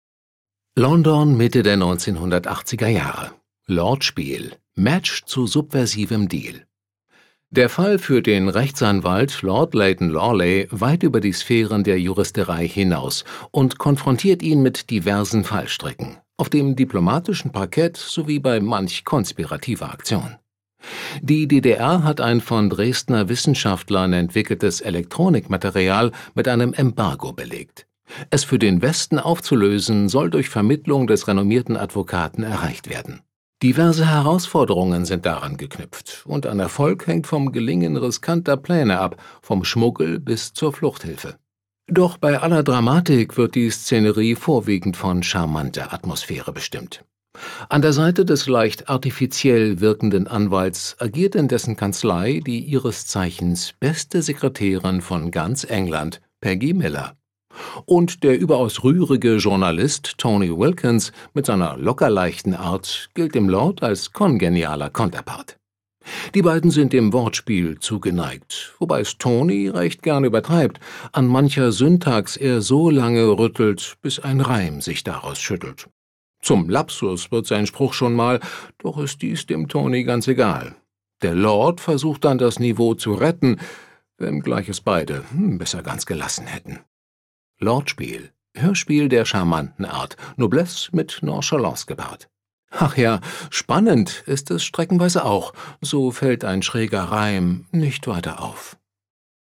sehr variabel
Mittel plus (35-65)
Audio Drama (Hörspiel)